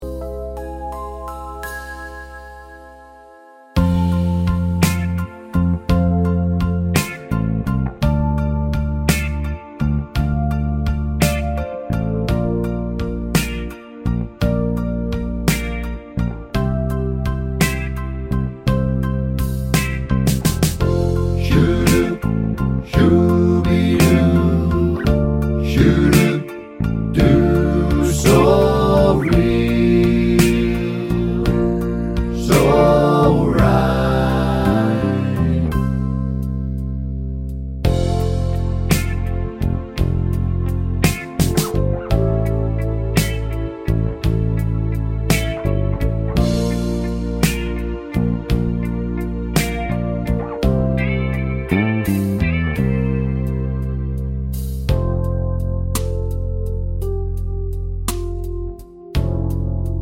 no sax Country (Male) 4:14 Buy £1.50